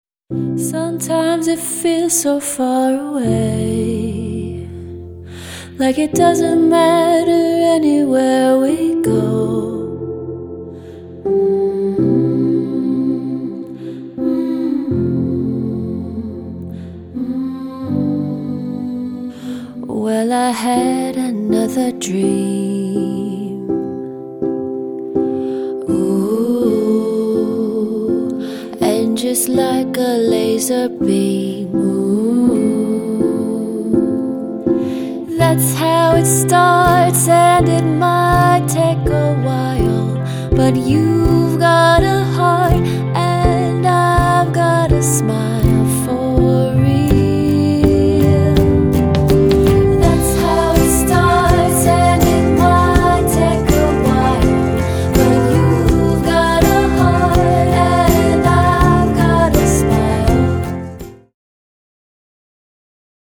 Ballad – Page 3 – Got Vocals